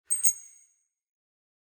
main_kunfun_btn.mp3